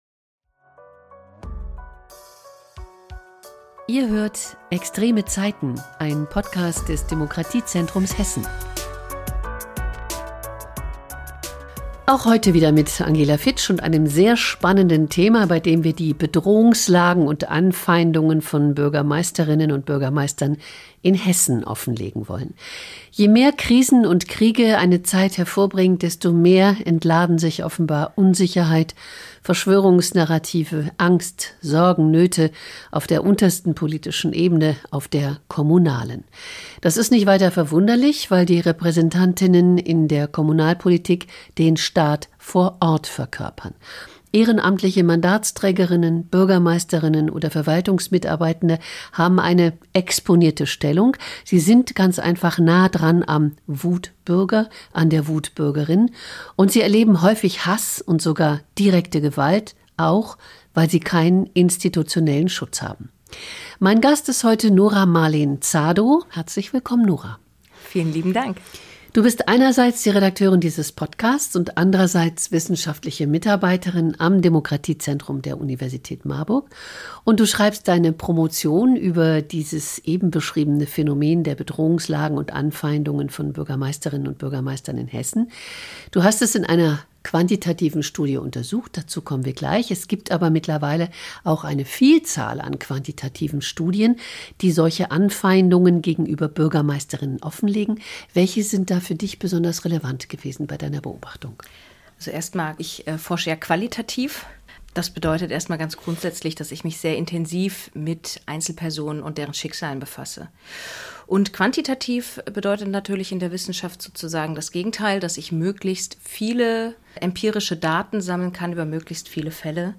Dabei wird deutlich: Die Bedrohungslage hat sich in den vergangenen Jahren dramatisch verschärft und stellt nicht nur eine Gefahr für die betroffenen Amtsträger*innen dar, sondern untergräbt auch die demokratische Kultur in unseren Kommunen. Im Gespräch diskutieren wir die Hintergründe dieser Entwicklung und schauen auf konkrete Erscheinungsformen der Anfeindungen – von verbalen Attacken in Ratssitzungen über Hassnachrichten in sozialen Medien bis hin zu physischer Gewalt.